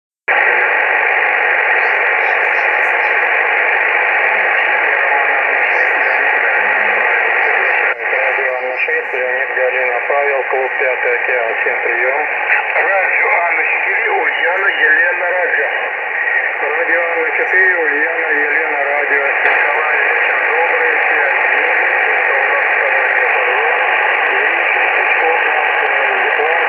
Для примера возьму диапазон 80М (согласование на пределе, КСВ=2.2)
В режиме телефонии:
В середине записи - приём по симметричной линии, по краям файла - приём с отключенной одной из клемм линии (эквивалент работы LW).